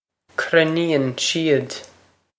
Pronunciation for how to say
krin-een she-ud
This is an approximate phonetic pronunciation of the phrase.